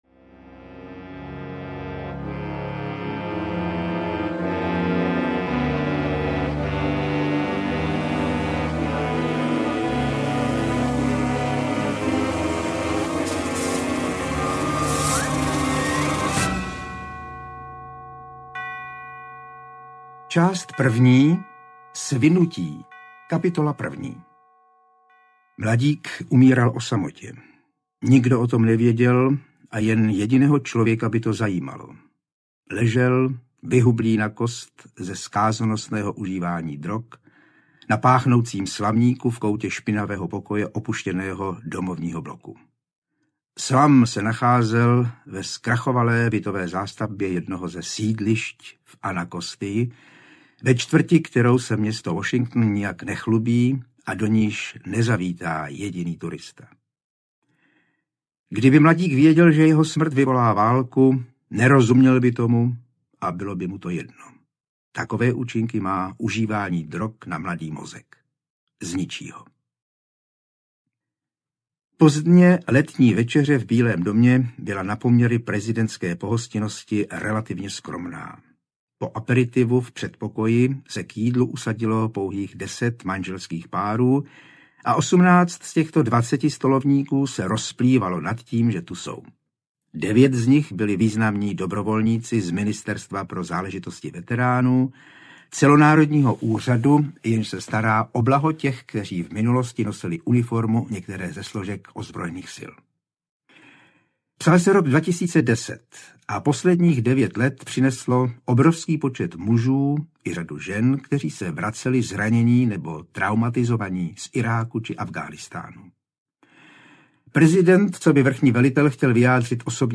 Kobra audiokniha
Ukázka z knihy